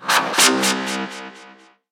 1 channel
laservkl.ogg